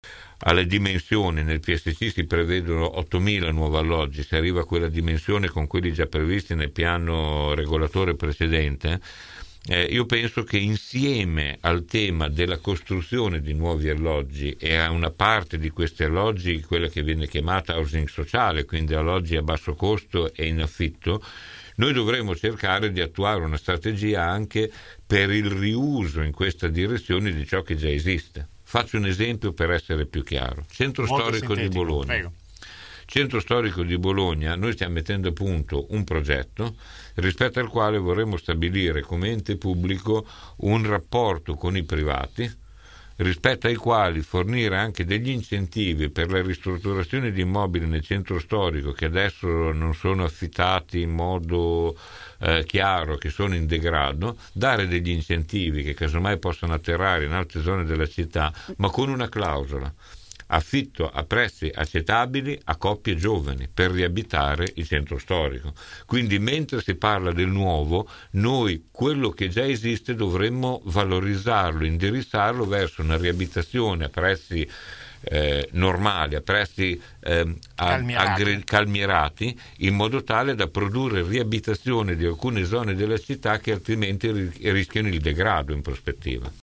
17 nov. – Questa mattina ai nostri microfoni l’assessore all’urbanistica, ambiente e sport del comune di Bologna Maurizio Degli Esposti ha risposto su una serie di punti a cominciare dalla questione dei “23 saggi” chiamati a “riprogettare Bologna” che, afferma l’assessore, non hanno alcun potere decisionale